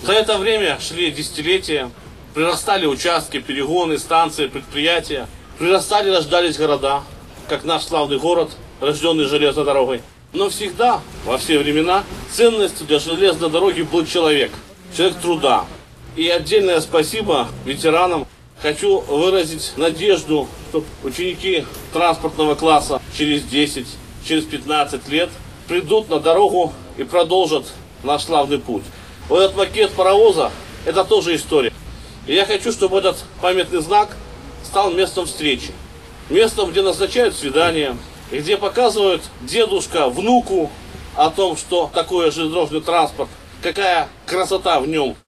В честь этого знаменательного события   на центральной площади города был открыт арт-объект.